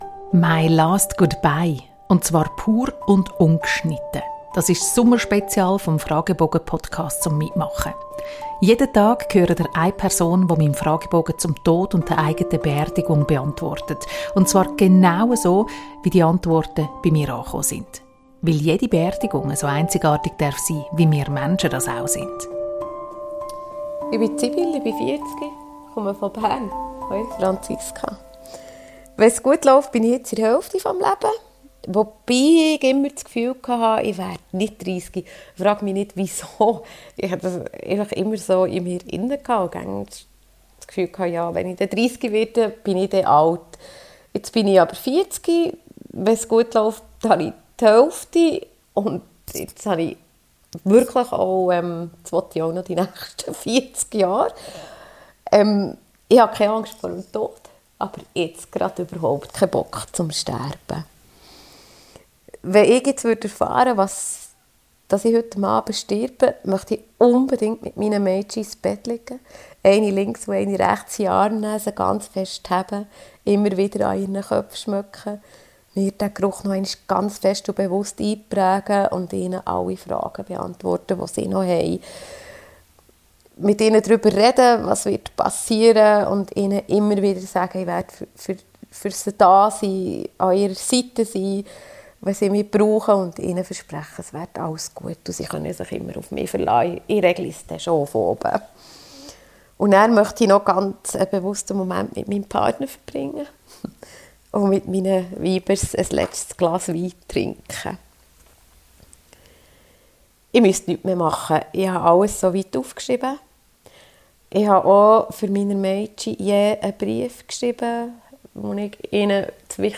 MY LAST GOODBYE - pur und ungeschnitten.